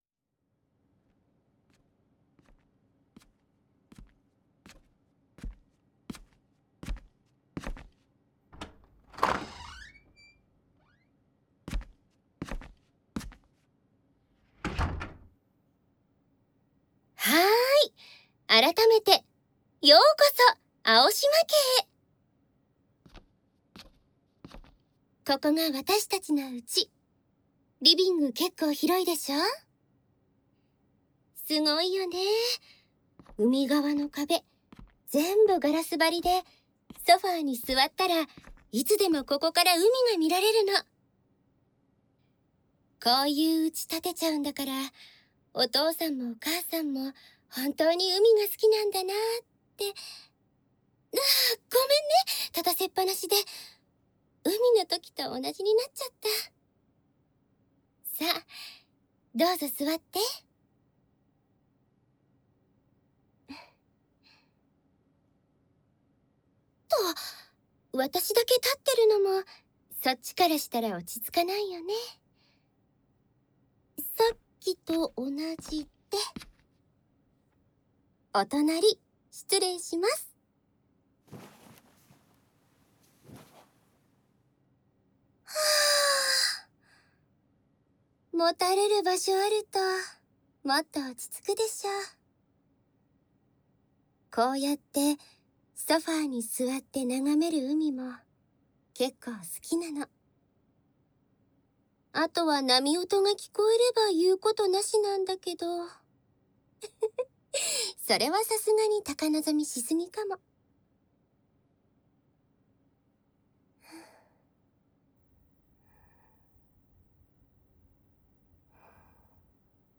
海姉妹・長女～おっとりお姉さんが波音と共にあなたを癒やすASMR～【CV.佐藤聡美】 - ASMR Mirror